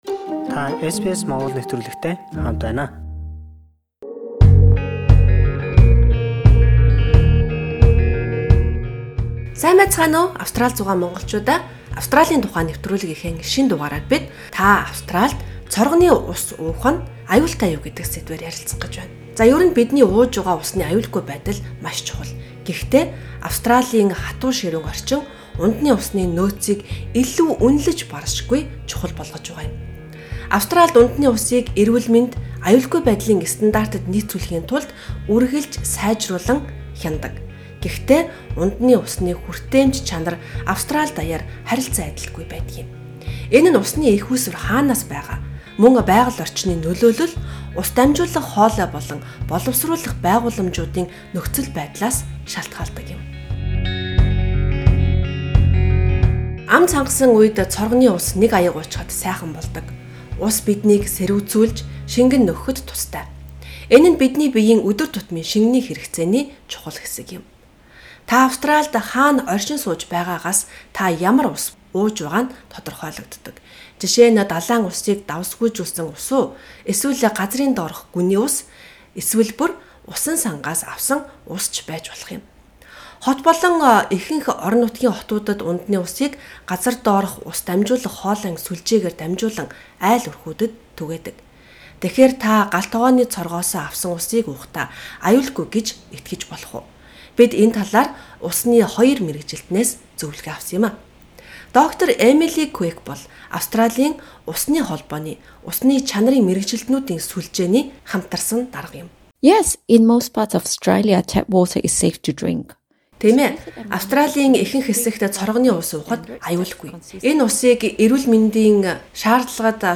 Улсын хэмжээнд ундны усны чанар, хүртээмж өөр өөр байдаг тул бид уухад аюулгүй эсэхийг яаж мэдэх вэ? Энэ дугаарт бид усны мэргэжилтнүүдтэй ярилцаж, энэ асуулт болон бусадтай асуултын хариуг авлаа.